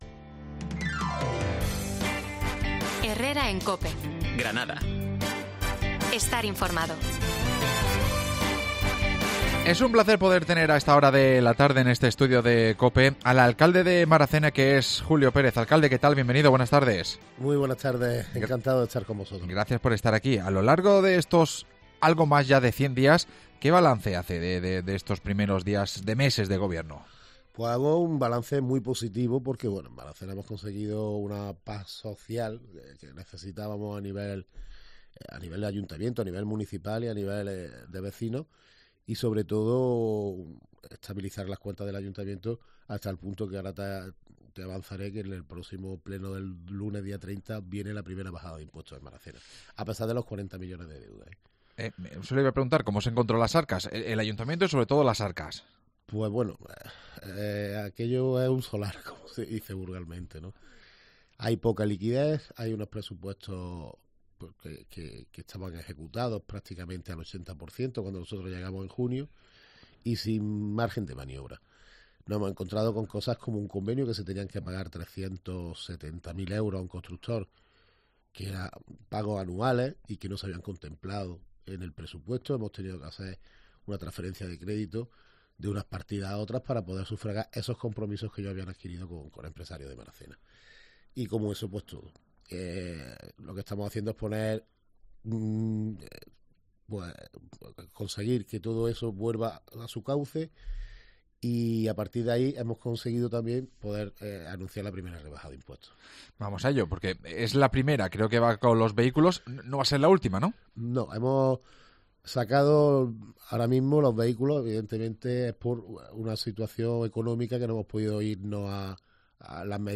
AUDIO: Julio Pérez, alcalde maracenero ha pasado por los micrófonos de COPE